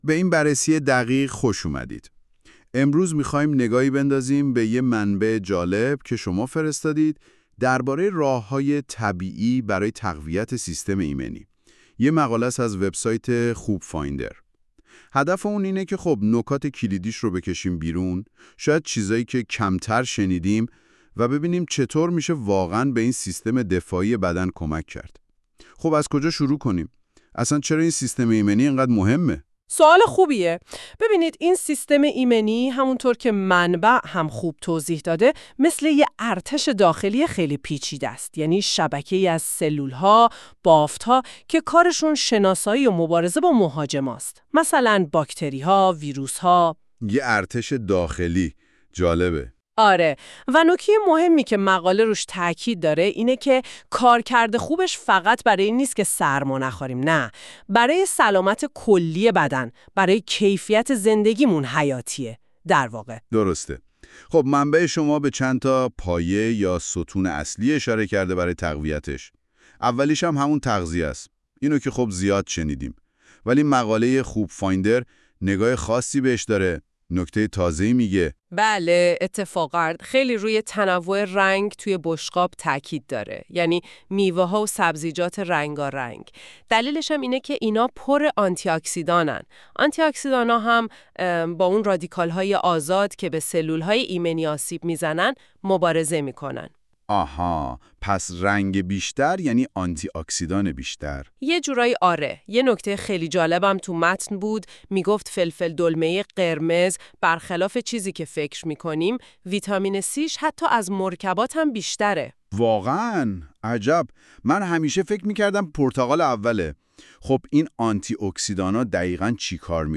این خلاصه صوتی به صورت پادکست و توسط هوش مصنوعی تولید شده است.